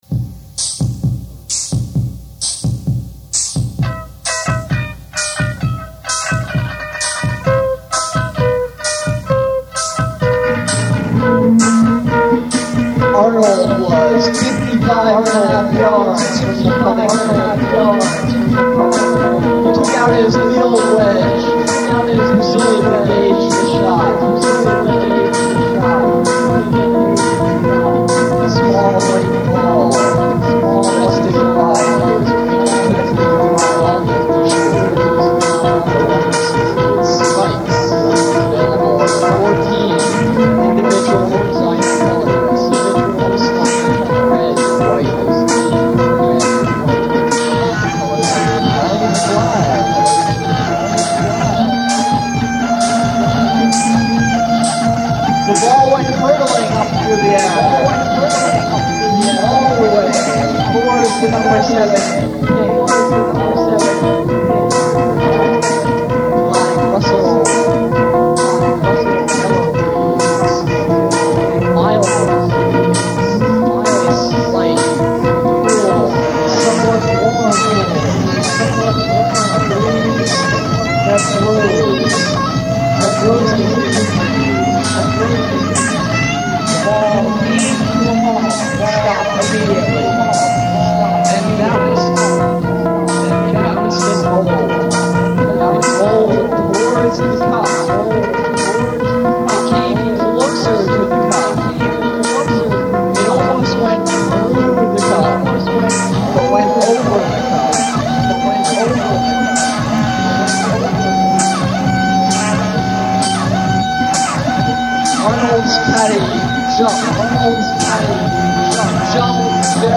guitar, bass, percussion
bass, vocals, percussion, autovari64, mirage